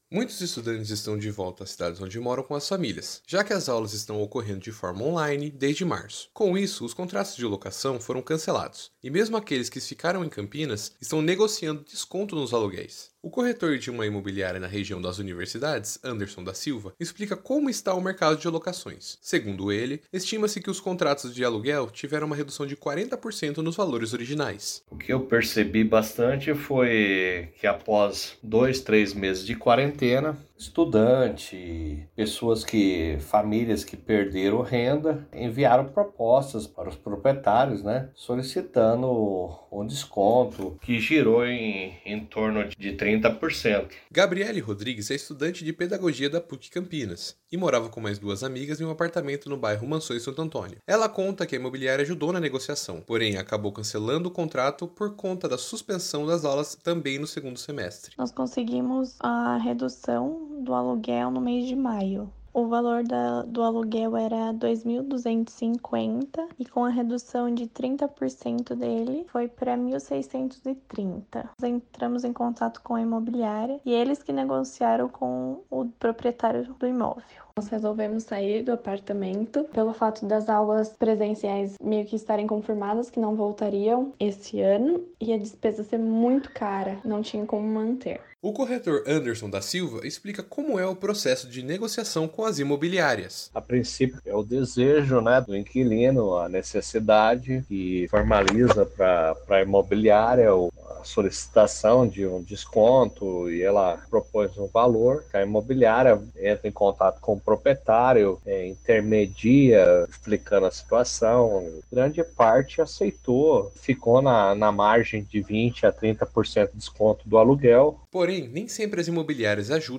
Abaixo, acesso à reportagem que foi ao ar no programa de rádio Giro RMC.